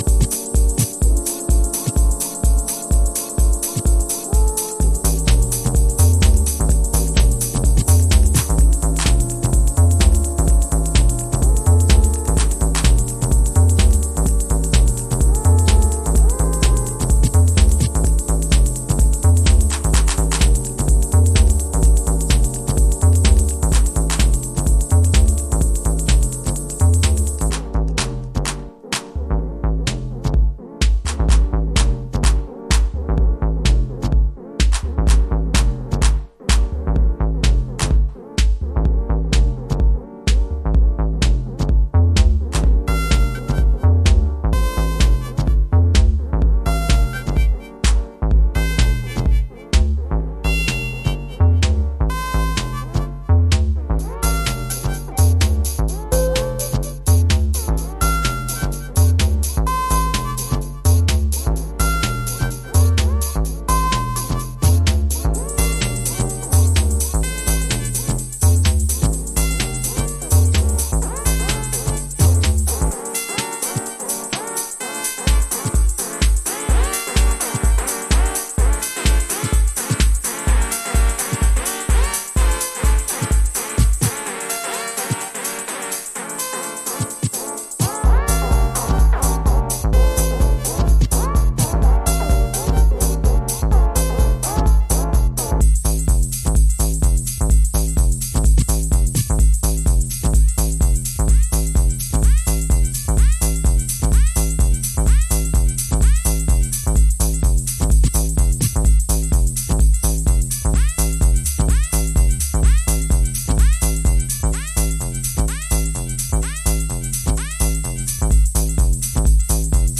House / Techno
ロングトーンのシンセでフローティン、しっかりシンベでグルーヴする